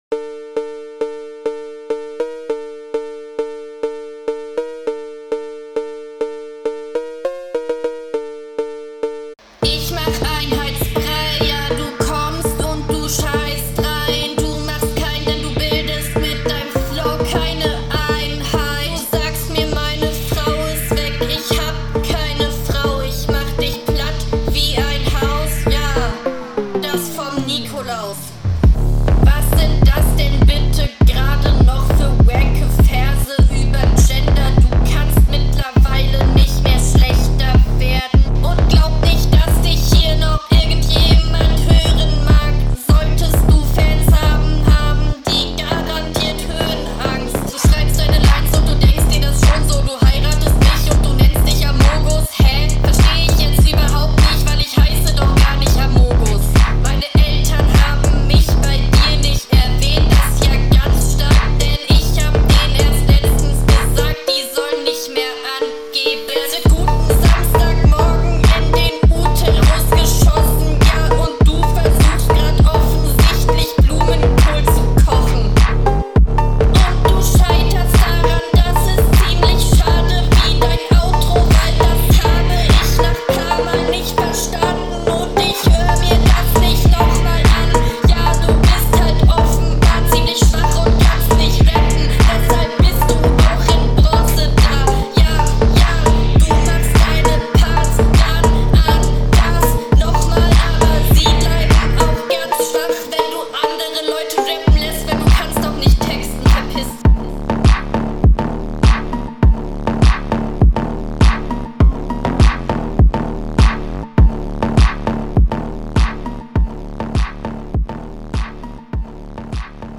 Ich sag dir wie's is Brudi, das klingt halt leider echt nicht geil.